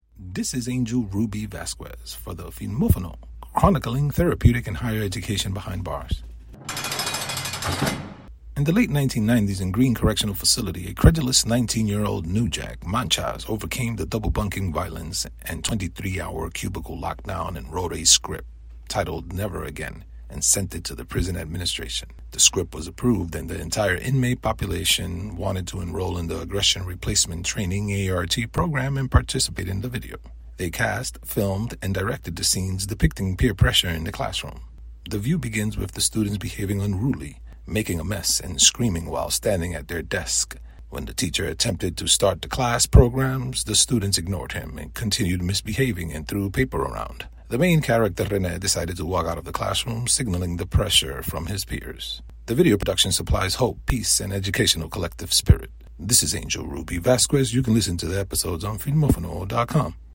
“Never Again” is a non-fictional podcast drama hosted through the biographer’s voice in an episodic narrative.
Additional Sound Effect: Prison Cell Door Sound Effect